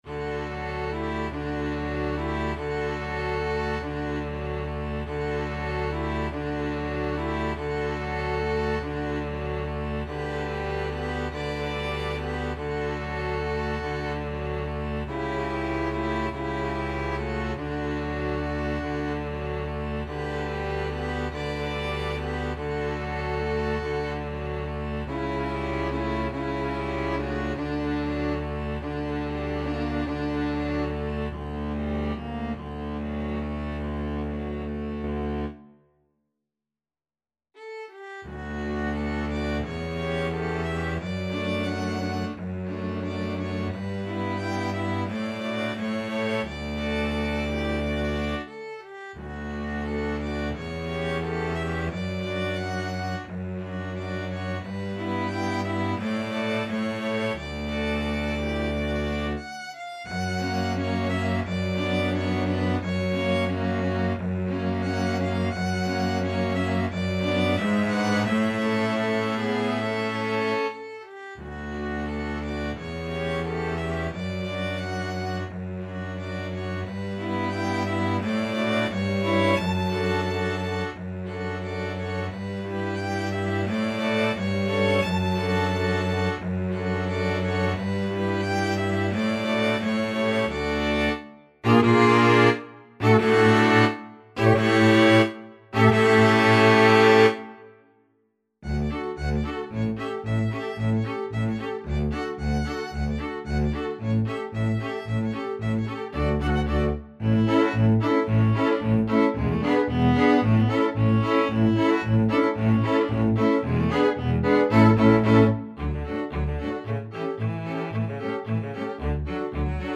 Free Sheet music for String Ensemble
Violin 1Violin 2ViolaCelloDouble Bass
3/4 (View more 3/4 Music)
D major (Sounding Pitch) (View more D major Music for String Ensemble )
Andante sostenuto (.=48)
Classical (View more Classical String Ensemble Music)